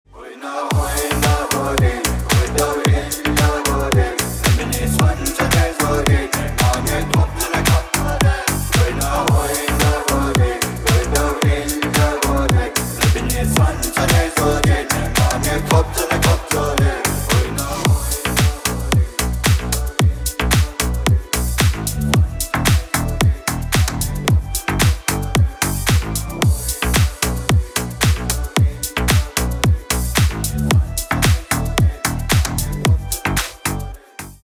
Украинские
ритмичные